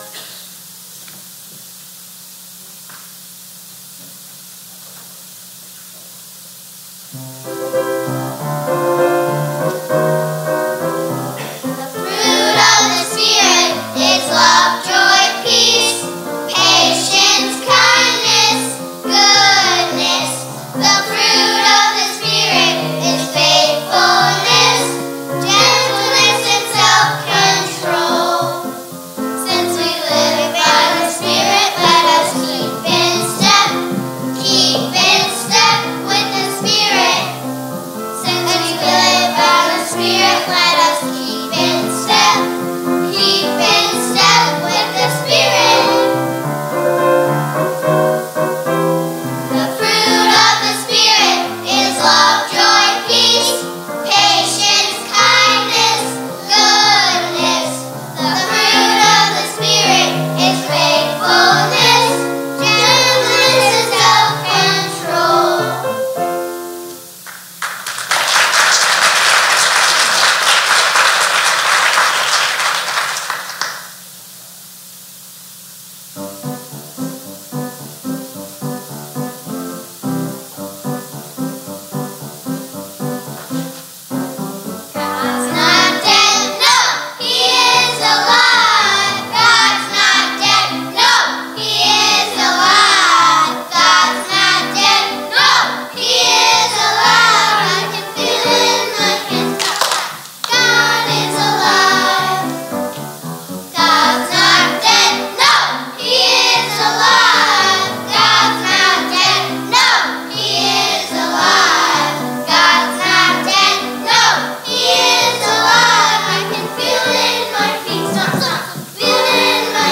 Sermons | Randolph First Reformed Church